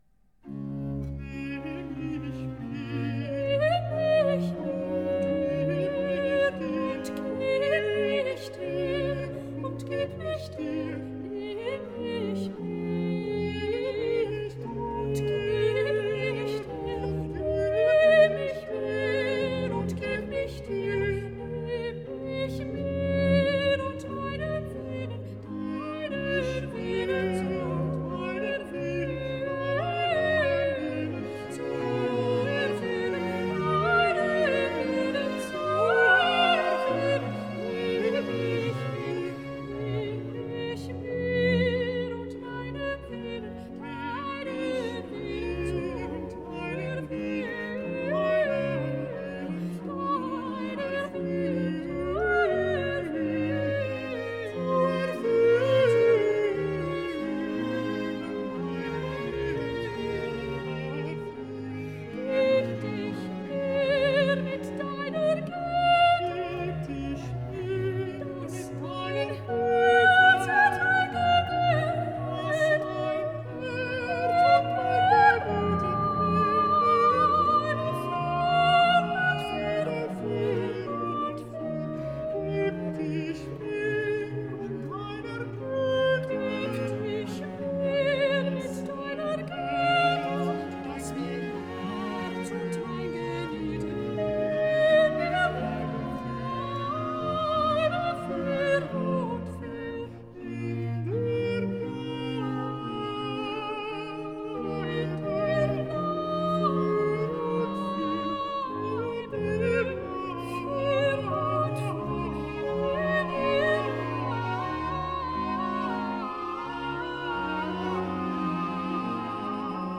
Aria - Duetto Soprano-Alto